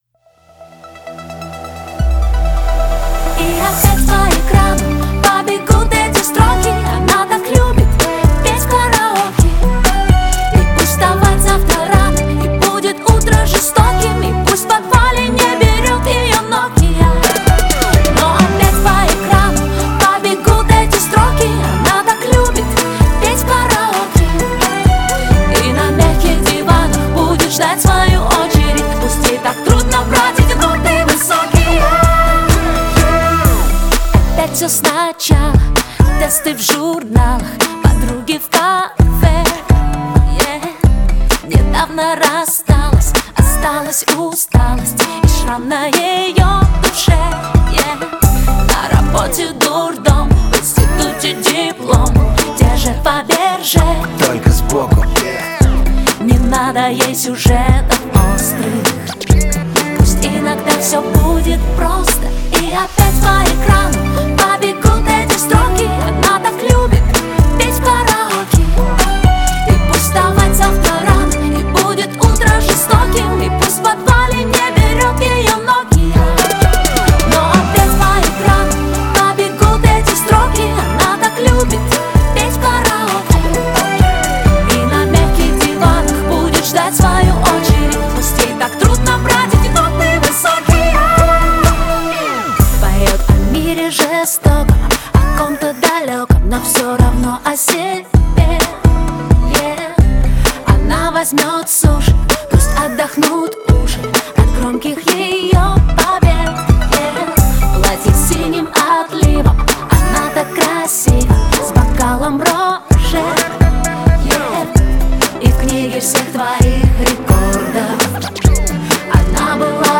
Категория: хип-хоп